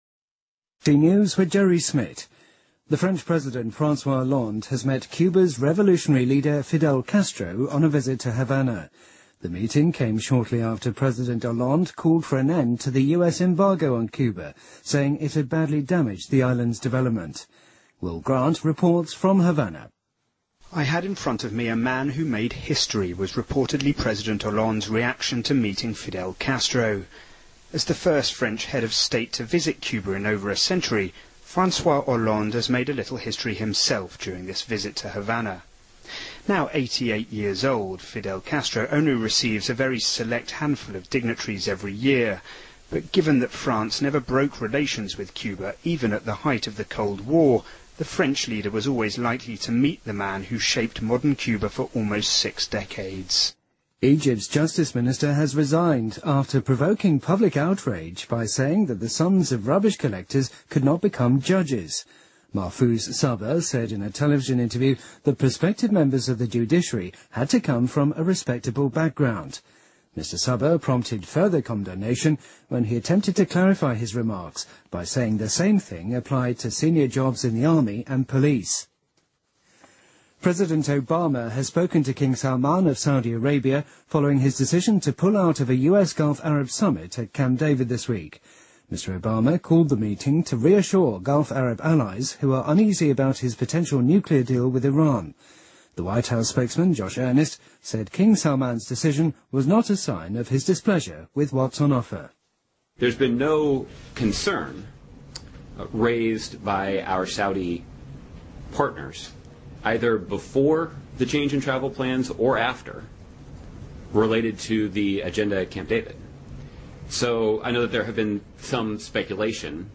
BBC news,法国总统弗朗索瓦.奥朗德会见古巴革命领袖菲尔德.卡斯特罗